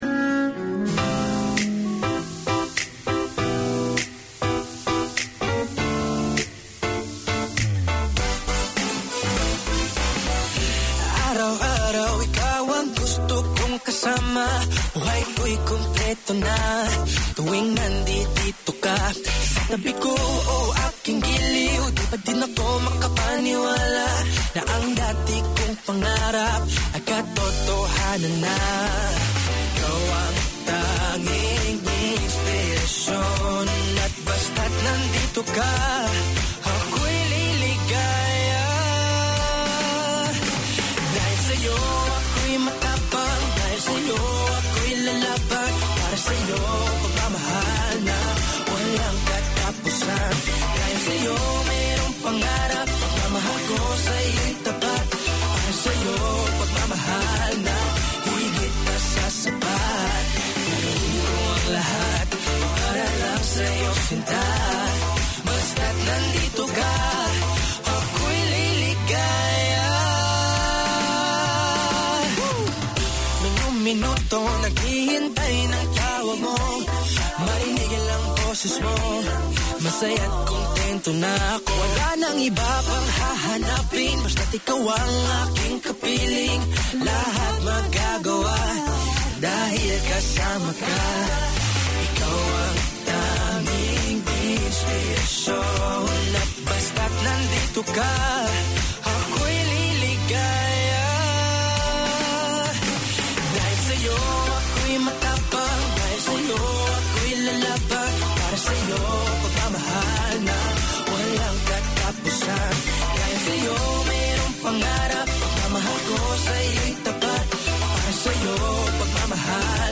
Showbiz radio with hot news, the buzz about our fave actors and OPM musical artists. Requests and dedications.